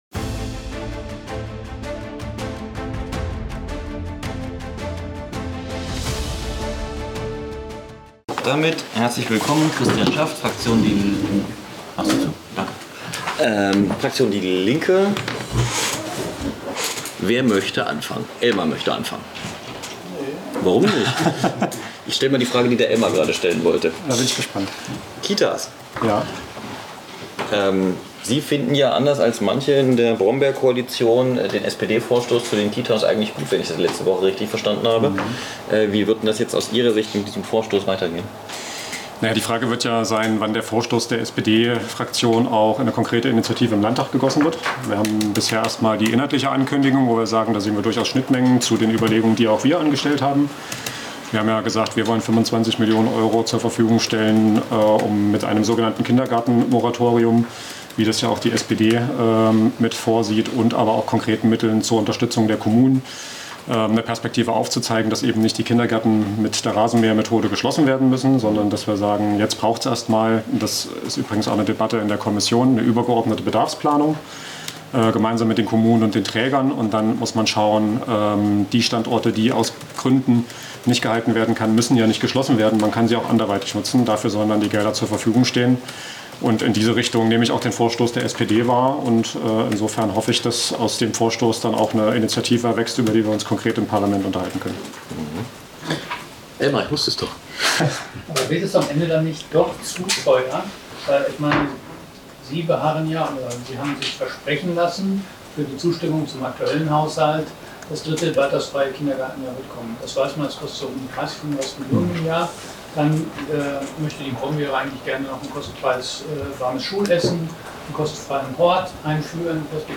Am 1. Oktober 2025 feierte die Th�ringer Landesregierung in der Gothaer Stadthalle den 35. Jahrestag der Deutschen Einheit. Im Mittelpunkt stand die Festrede von Ministerpr�sident Mario Voigt (CDU). Der H�hepunkt an diesem Abend war der gemeinsame Auftritt von Peter Maffay und Karat.